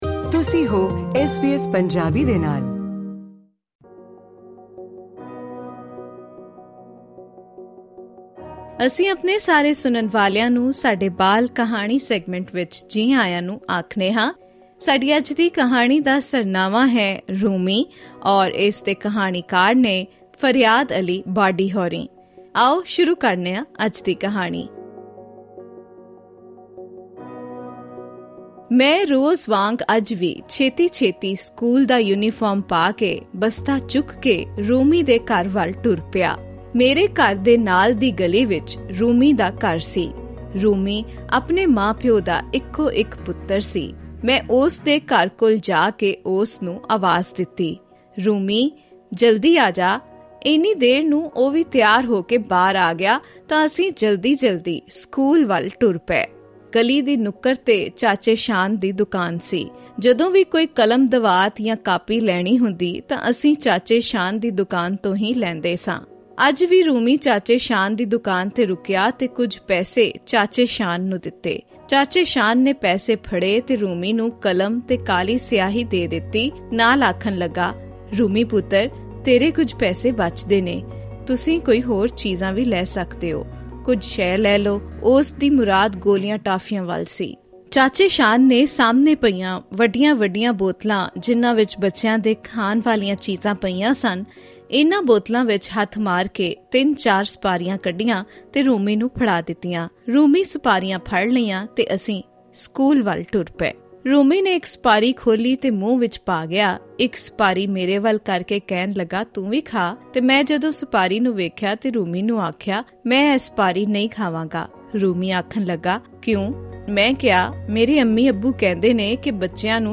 Listen to the story titled 'Rumi' in this week's episode of SBS Punjabi's 'Bal Kahani' series.